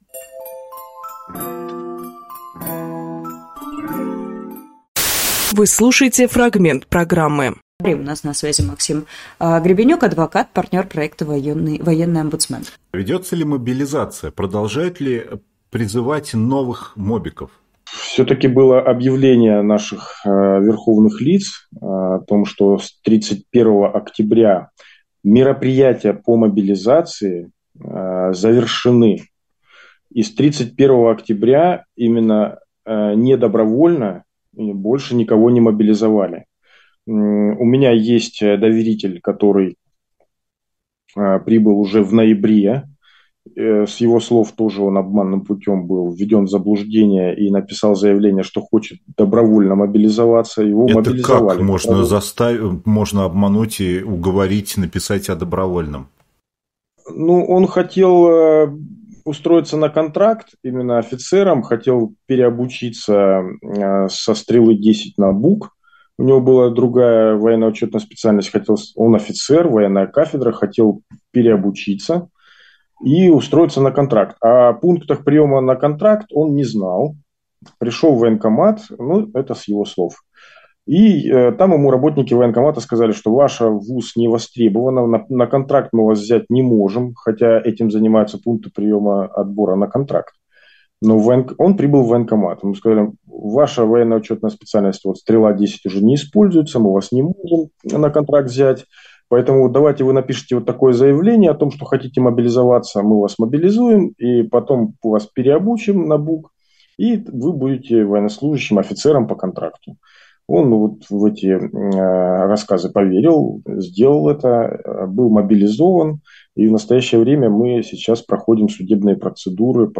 Фрагмент эфира от 03.04.23